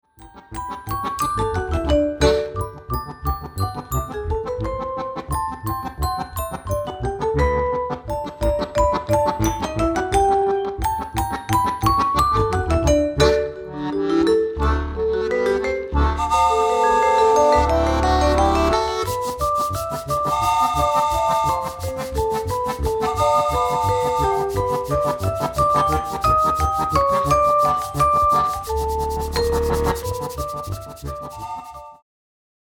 die Steirische Harmonika